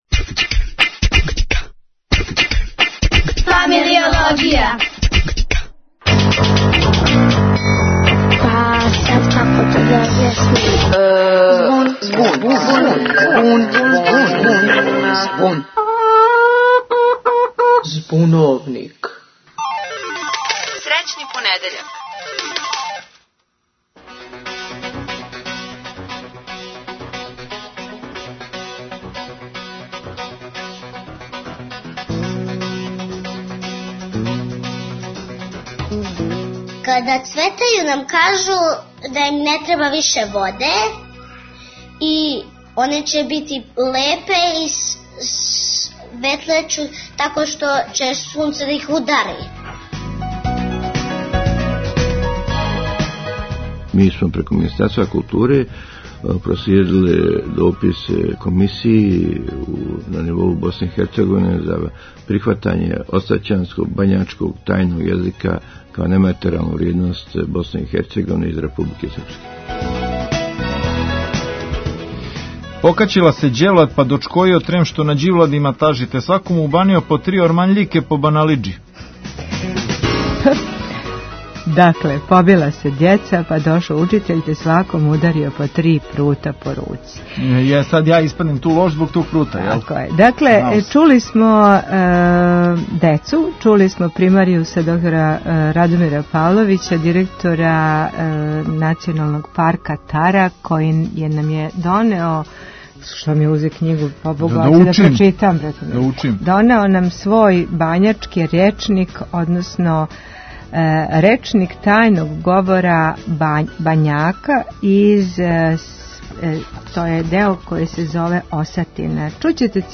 Говоре предшколци